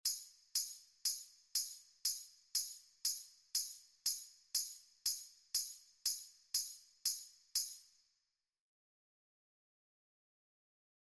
A continuación estarán los audios para escuchar los resultados sonoros de cada línea rítmica.
Maracas
OBtnC1zWU8X_Base-ritmica-Maracas.mp3